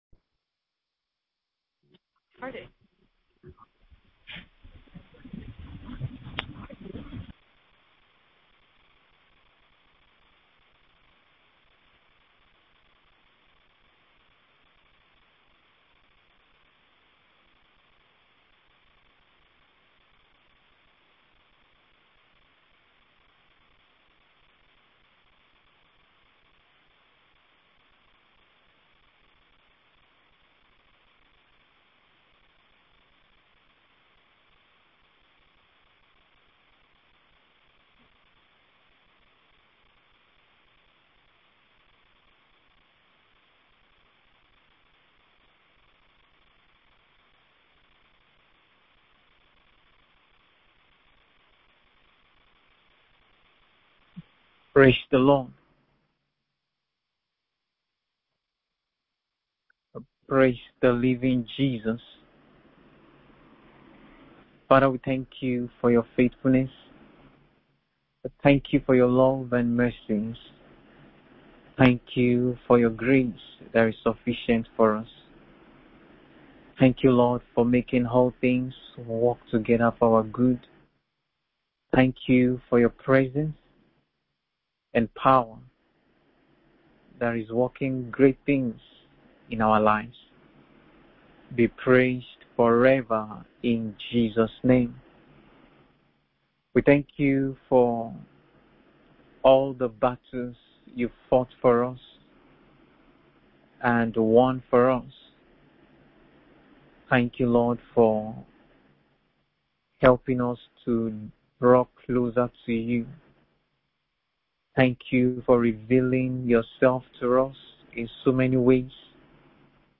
BIBLE STUDY_ REASONS WHY PERSECUTORS ATTACK CHRISTIANS – 1) FOR BREAKING RELIGIOUS TRADITIONS, 2) FOR BREAKING RELIGIOUS DOCTRINES, 3) RELIGIOUS JEALOUSY, 4) FEAR OF COMPETITION